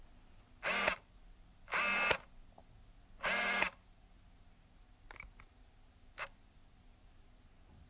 Tiếng Camera DSLR, Máy ảnh cơ Focus, lấy nét…
Tiếng Lấy nét, Focus, Click… Camera máy ảnh Gogogogogogogo… sound effect
Thể loại: Tiếng đồ công nghệ
Description: Tiếng Camera DSLR, máy ảnh cơ khi focus, lấy nét tạo ra những âm thanh đặc trưng của động cơ, mô-tơ ống kính hoạt động.
tieng-camera-dslr-may-anh-co-focus-lay-net-www_tiengdong_com.mp3